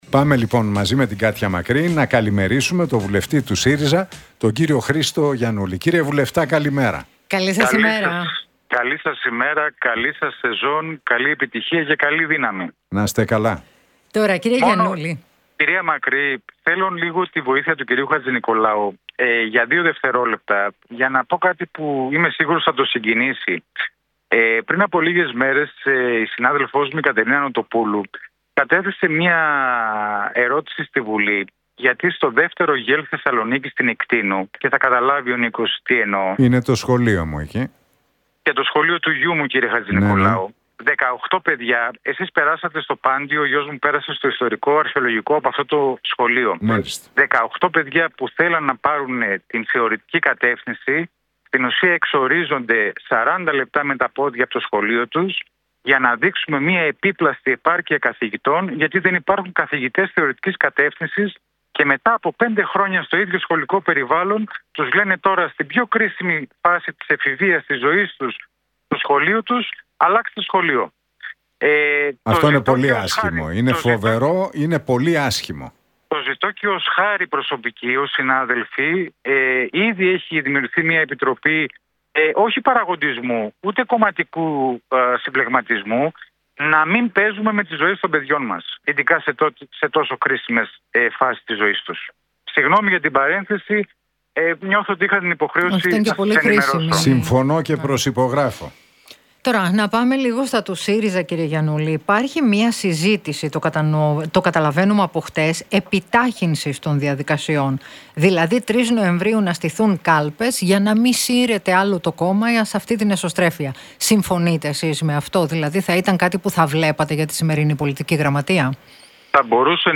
Γιαννούλης στον Realfm 97,8: Δεν είμαι υπέρ των διαγραφών - Οφείλει να είναι υποψήφιος ο Στέφανος Κασσελάκης